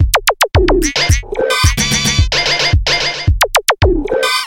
它 Moombahton鼓
Tag: 110 bpm Dubstep Loops Drum Loops 1.47 MB wav Key : Unknown